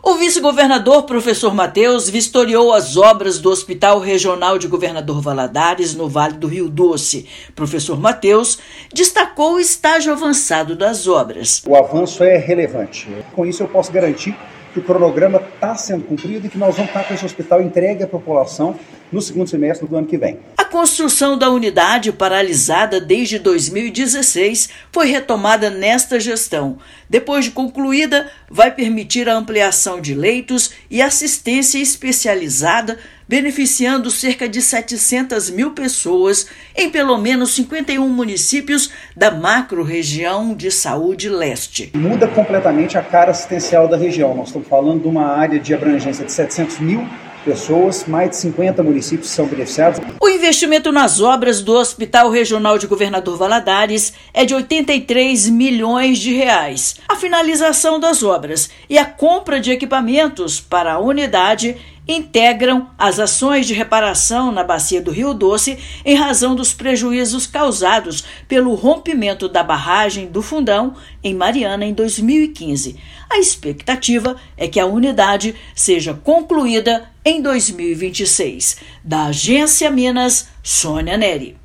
Unidade vai beneficiar mais de 700 mil pessoas nas regiões Leste e Vale do Rio Doce com atendimento de urgências e emergências, internação, exames de imagem e cirurgias. Ouça matéria de rádio.